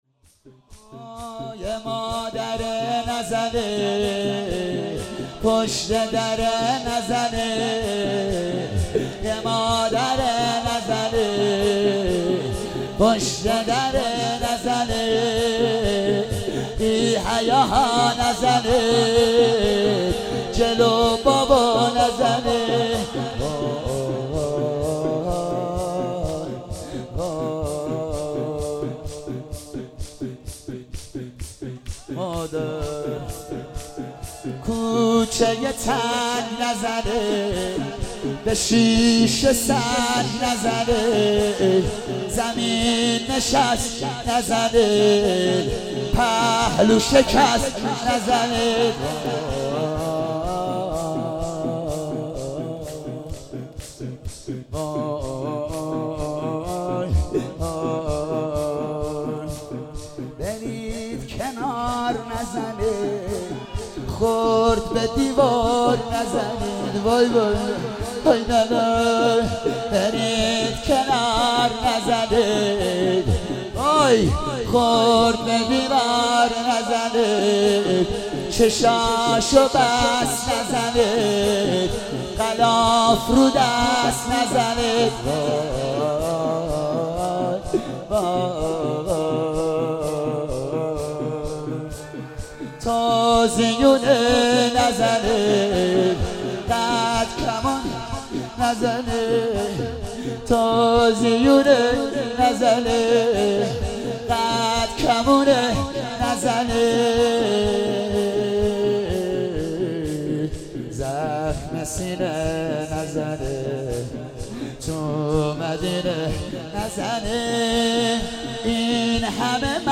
ایام فاطمیه 95
شور - یه مادره نزنید